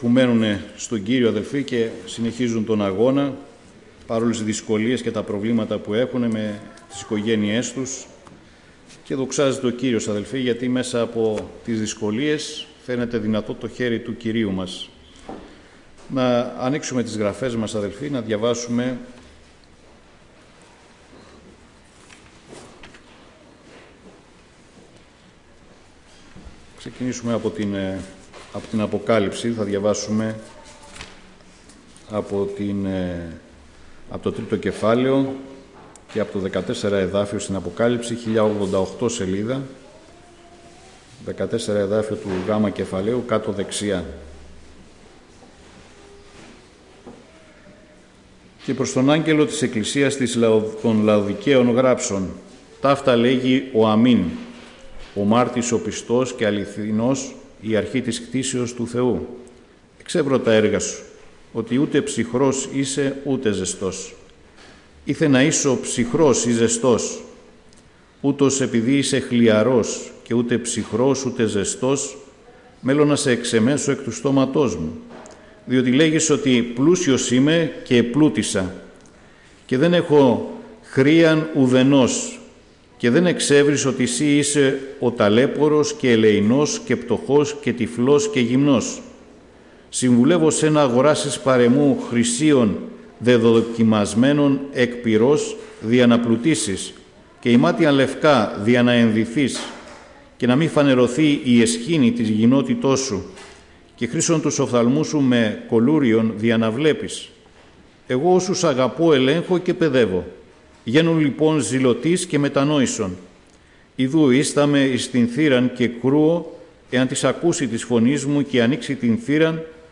Κήρυγμα Δευτέρας | Αποκάλυψη γ' [3] 14-22